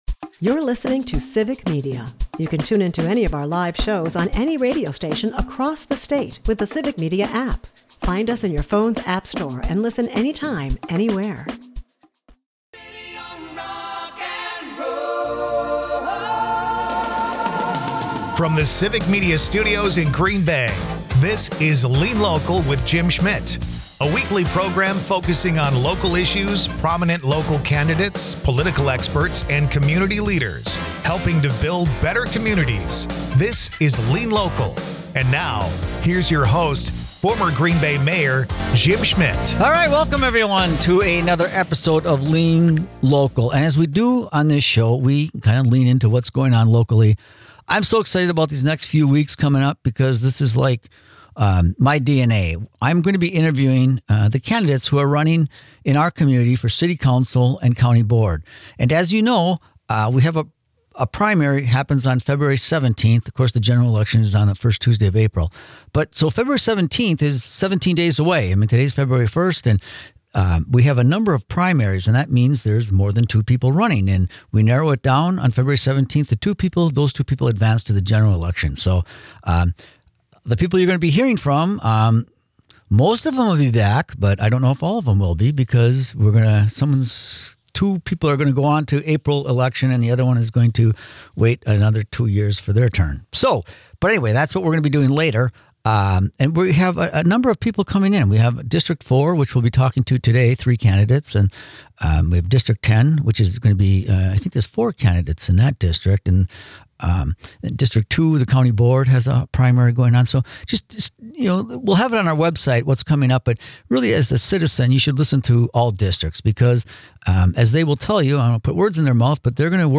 This week on Lean Local, Jim Schmitt hosts a conversation that hits especially close to home.
Each candidate joins the program to share their perspective and priorities for the district. To keep things fair and informative, Jim asks each candidate the same core questions: why they’re running for office, what they hope to accomplish if elected, and how they plan to communicate with constituents.